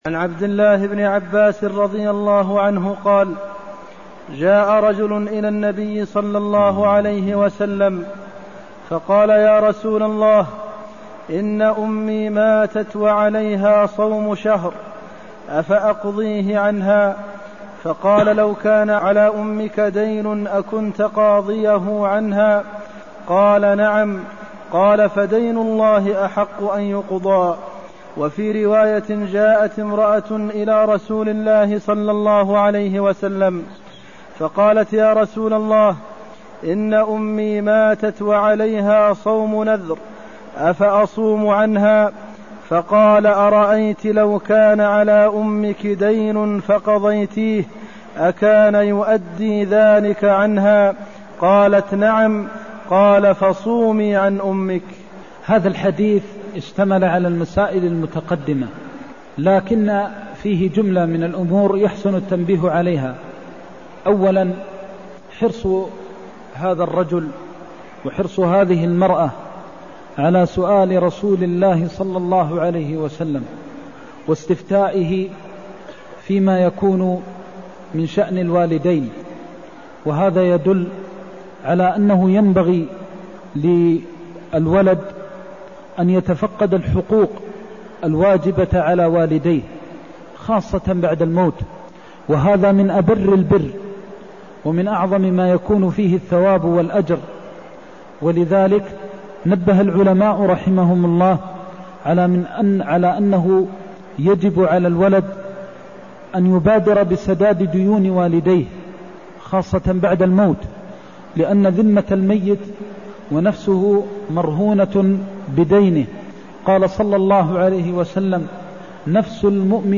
المكان: المسجد النبوي الشيخ: فضيلة الشيخ د. محمد بن محمد المختار فضيلة الشيخ د. محمد بن محمد المختار إن أمي ماتت وعليها صوم شهر (184)